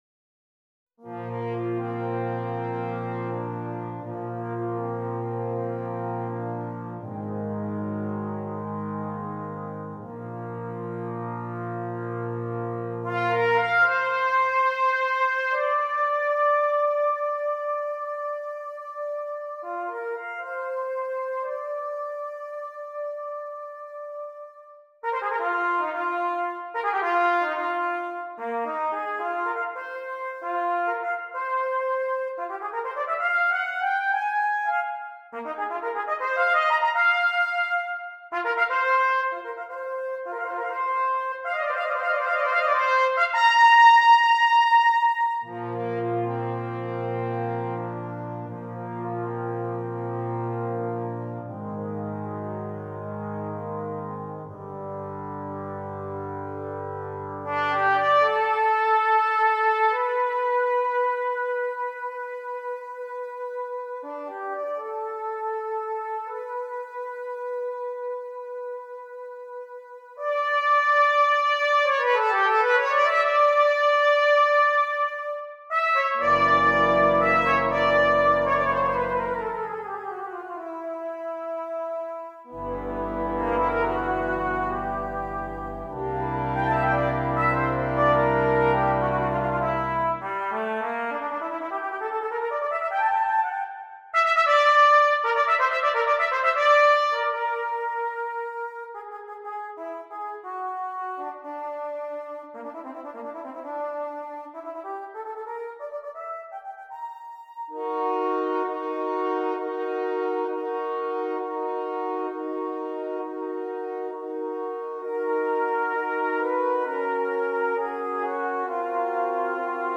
Brass Quintet and Solo Trumpet
Difficulty: Difficult Order Code